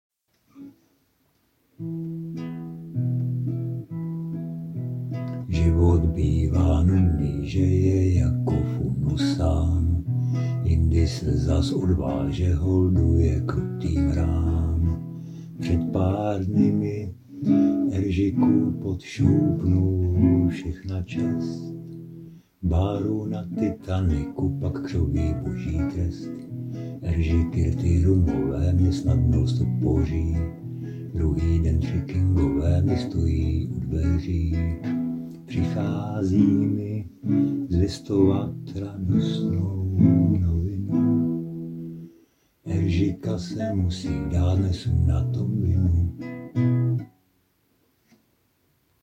Anotace: Jestli Elvise jenom unesly ufouni, tak se určitě co nevidět vrátí... nemáte zač:-) Hudba zatím jenom nástřel;-)
V té písničce se v jednu chvíli v celkem rychlém sledu vystřídá 7 ne úplně snadných akordů a číst k tomu text, který jsem zrovna dopsal a ještě se soustředit na to, abych udržel aspoň trošku nějakou melodii ve zpěvu... je to občas nad moje síly:-)
No a ten hlas... myslím, že je pořád stejnej.